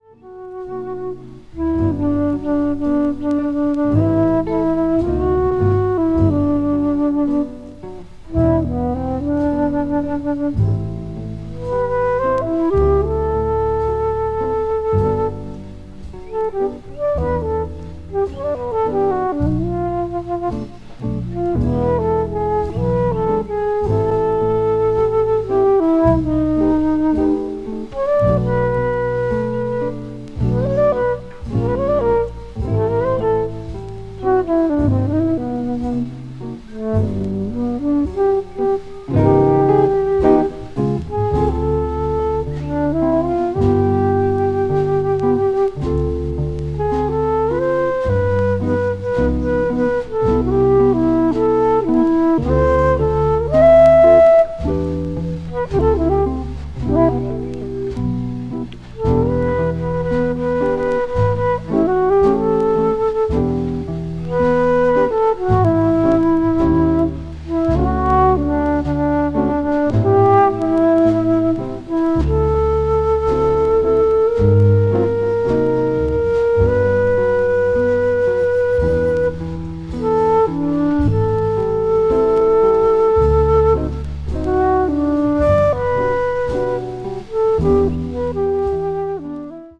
20年代後半のスタンダード・ナンバーをムーディに奏でたバラードA3も素敵です。
cool jazz   jazz standard   modern jazz